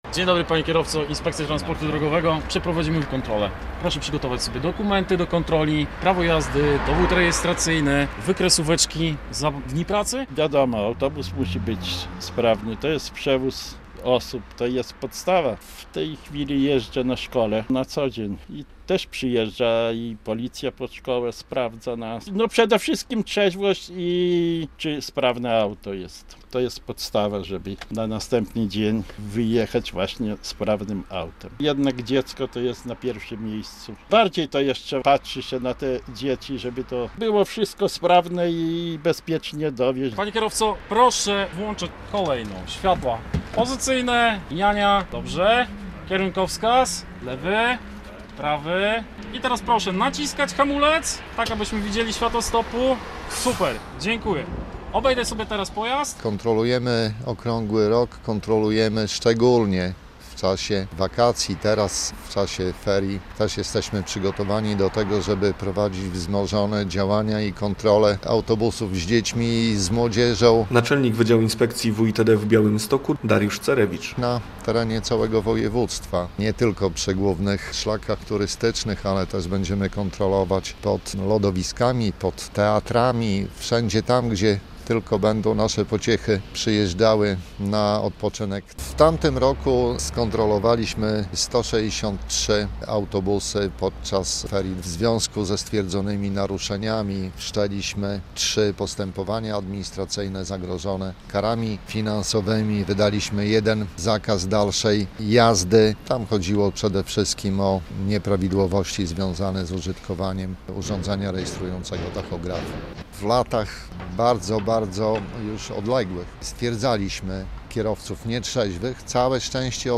Inspektorzy transportu drogowego kontrolują autobusy - relacja